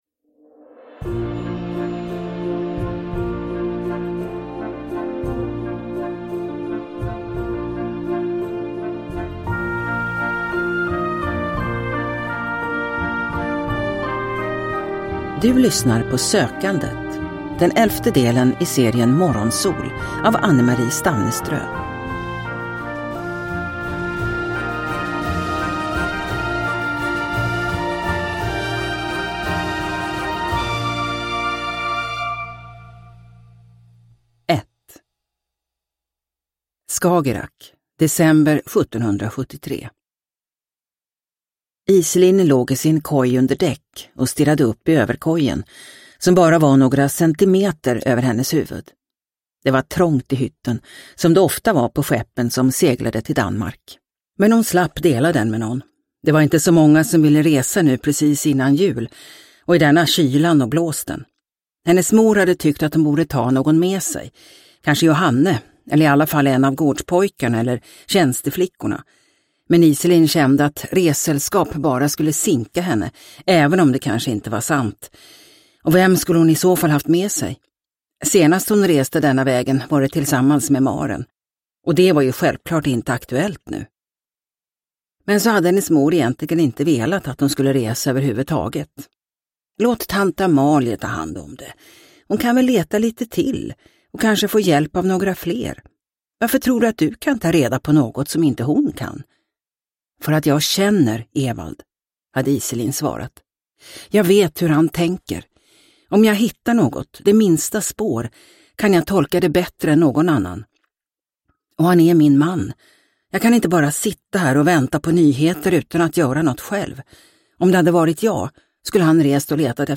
Sökandet – Ljudbok – Laddas ner